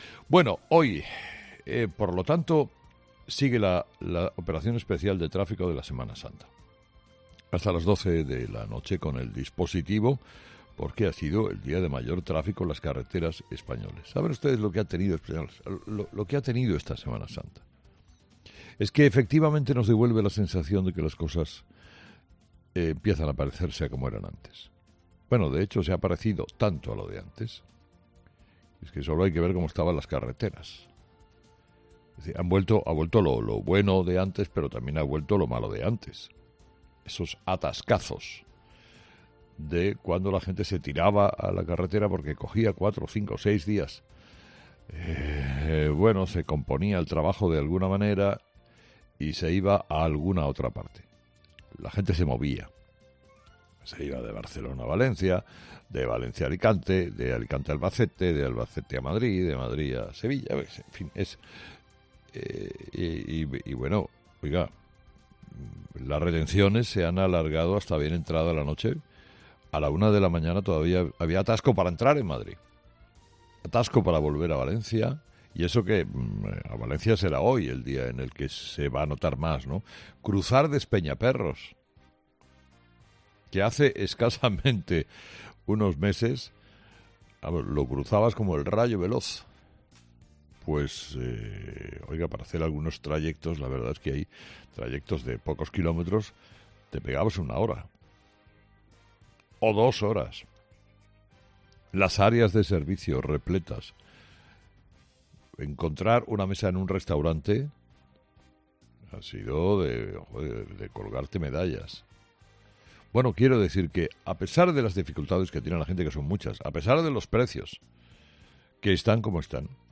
Carlos Herrera, director y presentador de 'Herrera en COPE', ha comenzado el programa de este lunes analizando las principales claves de la jornada, que pasan, entre otros asuntos, por el final de la Semana Santa, la retirada de las mascarillas y el futuro electoral en Andalucía.